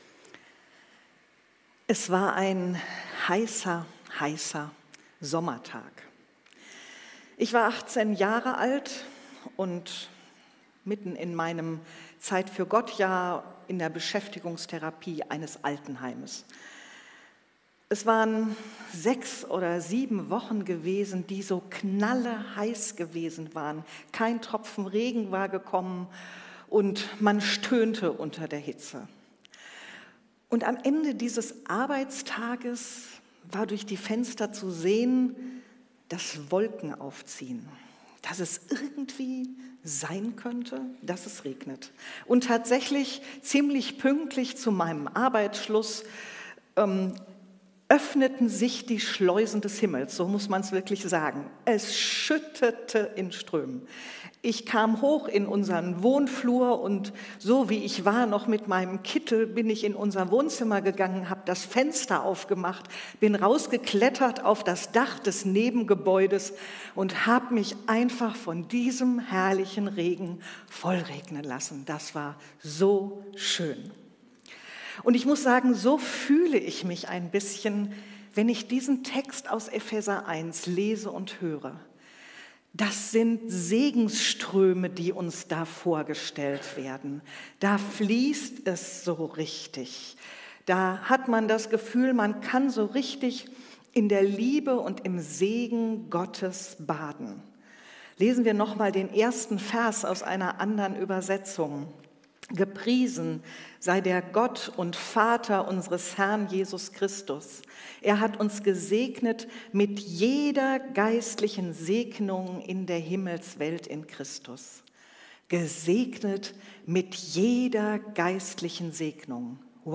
Gottesdienst